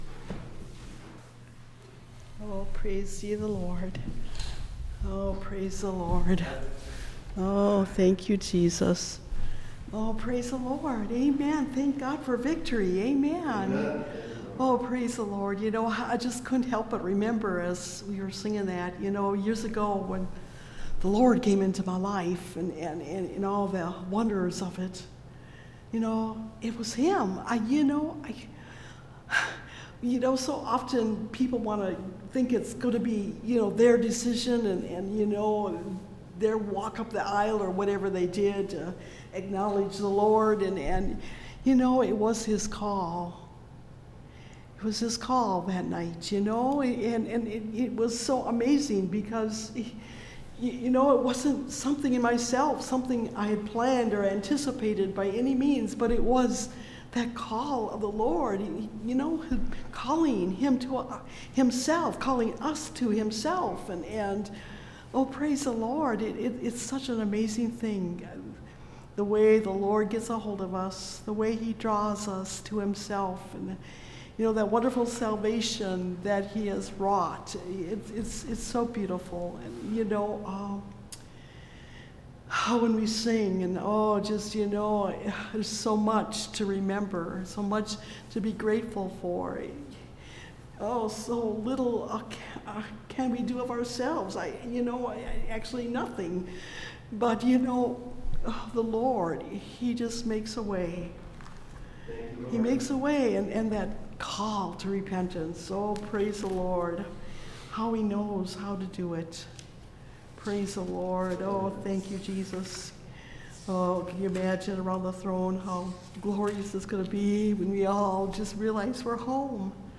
Behold The Stone (Message Audio) – Last Trumpet Ministries – Truth Tabernacle – Sermon Library